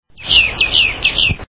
Basic Information: Bird Song recordings